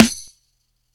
GSY_SNR_2.wav